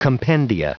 Prononciation du mot compendia en anglais (fichier audio)
Prononciation du mot : compendia